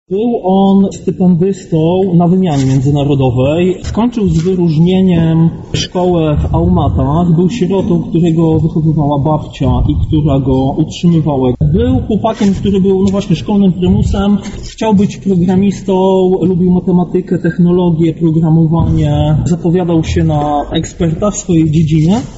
Manifestacja odbyła się pod siedzibą Polskiego Związku Łowieckiego w Lublinie.
protest-antylowiecki.mp3